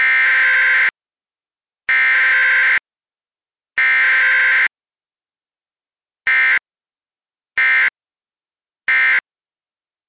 There is one second of blank audio between each section, and before and after each message.
Aural example of SAME transmission